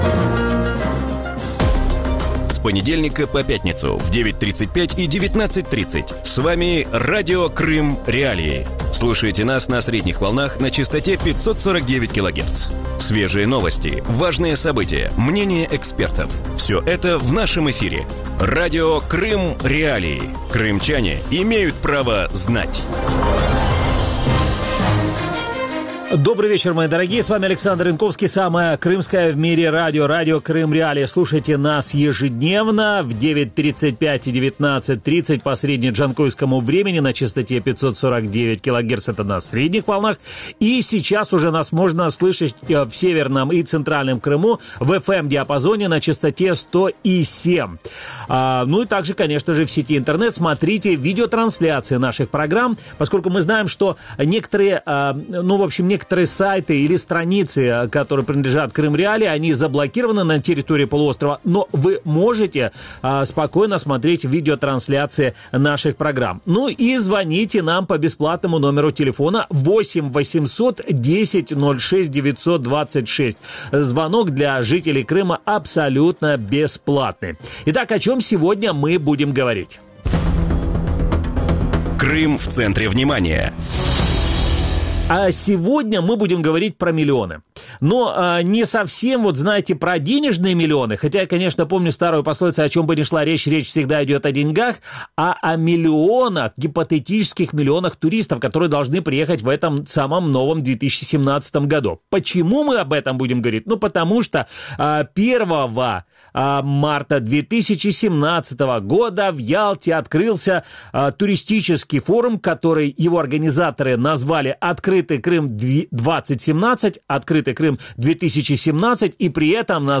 У вечірньому ефірі Радіо Крим.Реалії говорять про майбутній туристичний сезон у Криму. Чи може конкурувати півострів із російськими та зарубіжними морськими узбережжями, скільки туристів очікує російська влада Криму та як зміниться сервіс на кримських курортах?